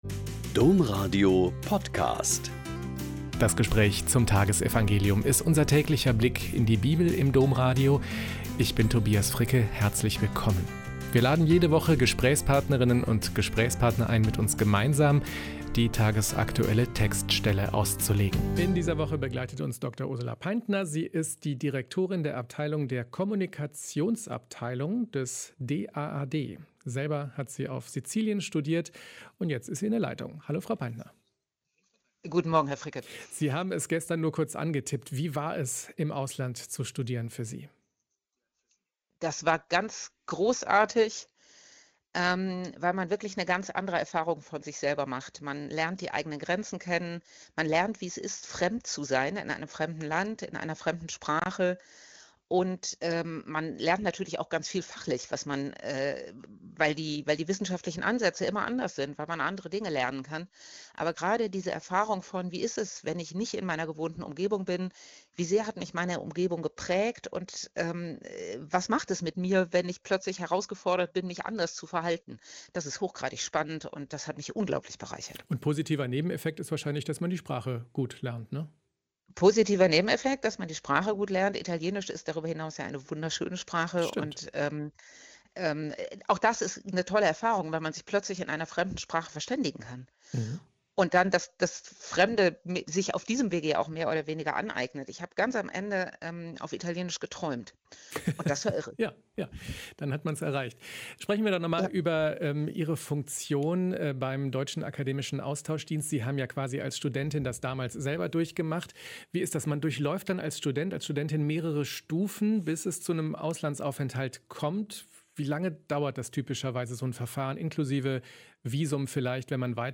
Lk 6,20-26 - Gespräch